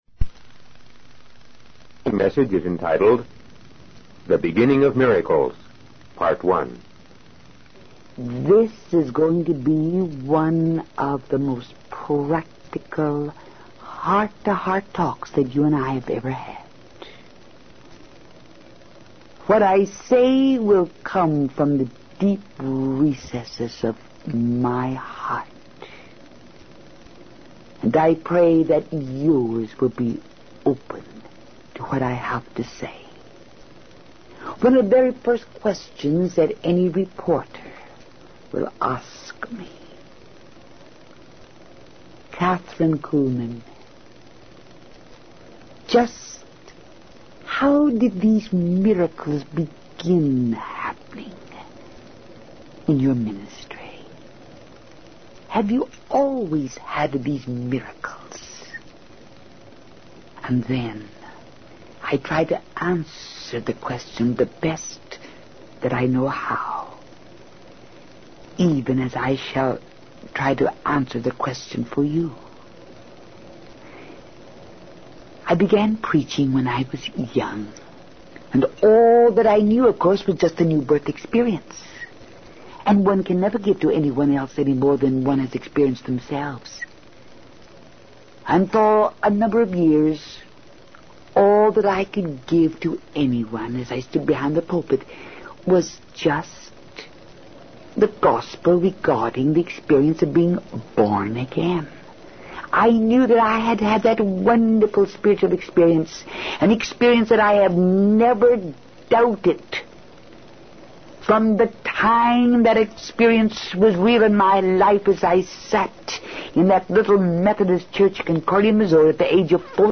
In this sermon, Catherine Kuhlman shares the story of how miracles began happening in her ministry. She reflects on her early years of ministry, when she was full of energy and hunger for God's word.